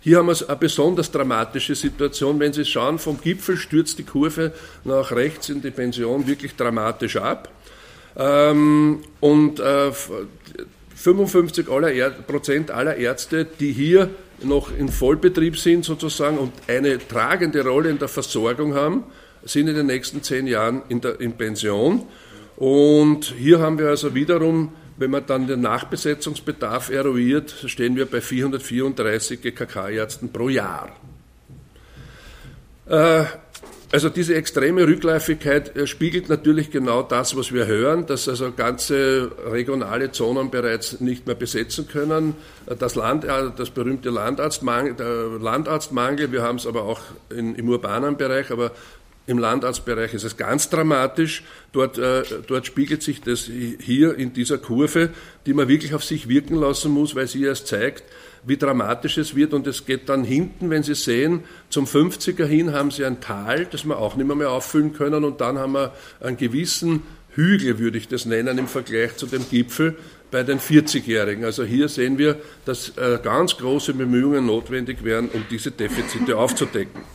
O-Töne (mp3)